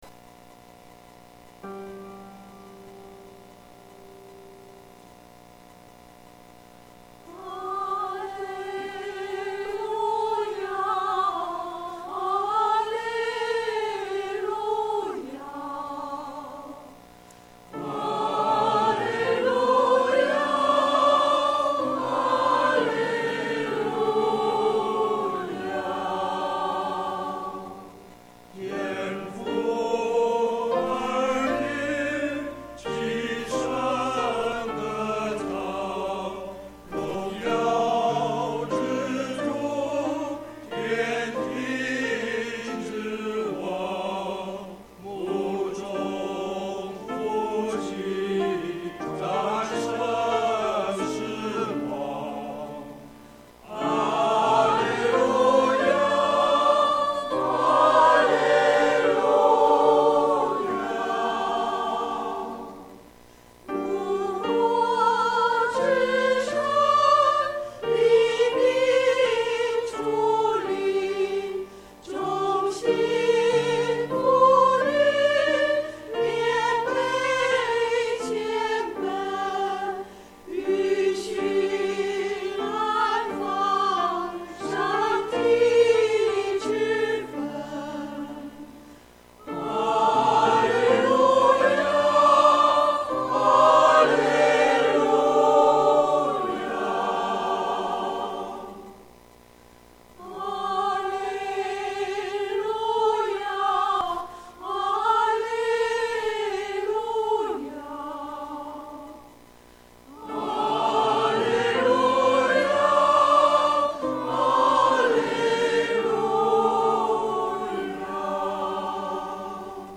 • 詩班獻詩